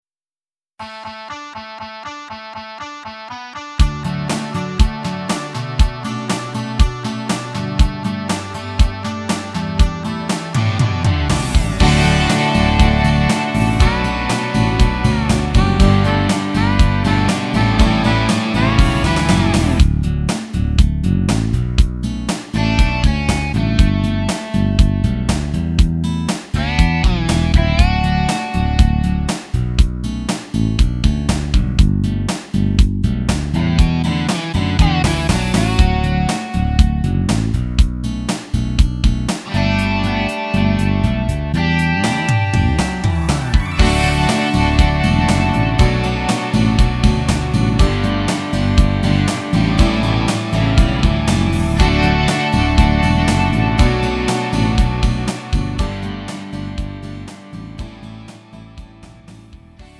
음정 -1키 2:45
장르 구분 Lite MR